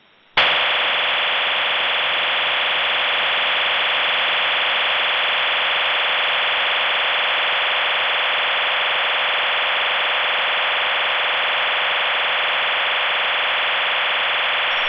Russian 128-tone OFDM waveform with 3 kHz BW idling Russian 128-tone OFDM waveform with 3 kHz BW sending traffic Russian 128-tone OFDM waveform with 3 kHz BW moving from idle into traffic state with modem resynch sequence
OFDM-128_idling.WAV